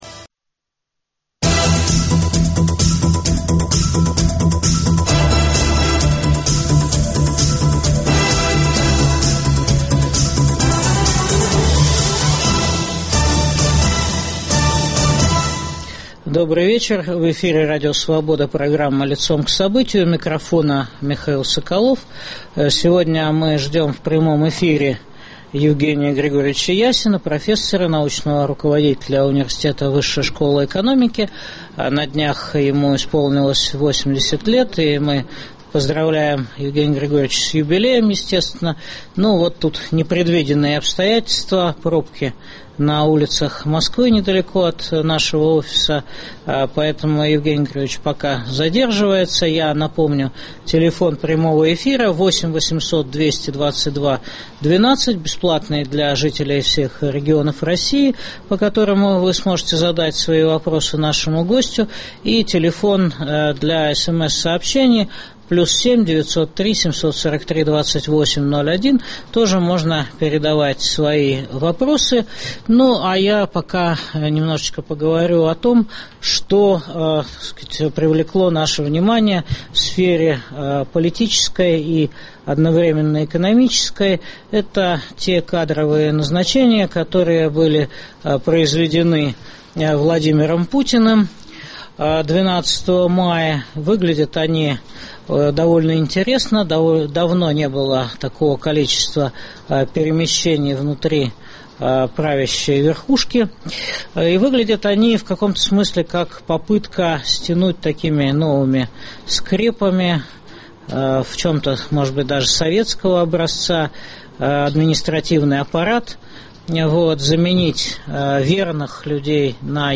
Выдержит ли экономика России экспансию? В студии Радио Свобода научный руководитель ГУ-ВШЭ профессор Евгений Ясин.